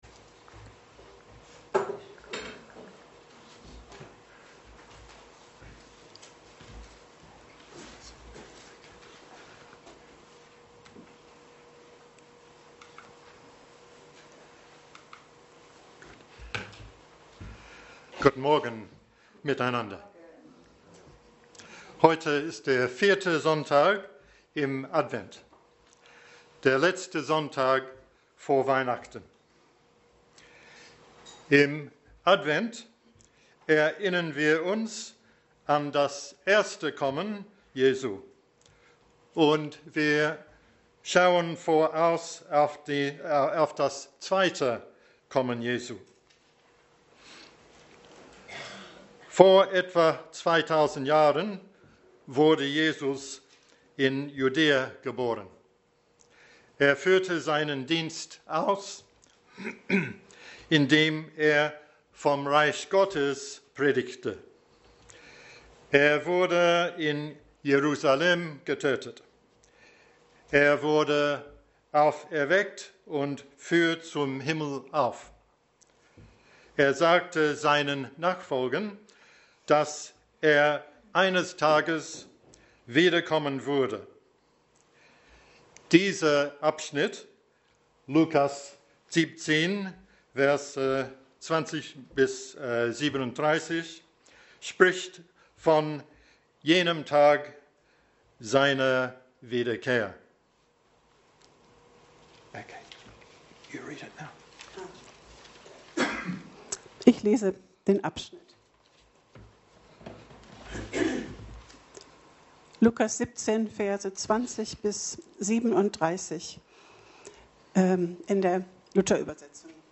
Dienstart: Predigt Themen: Person Jesu , Reich Gottes , Wiederkunft Jesu « Unerwartet … wundervoll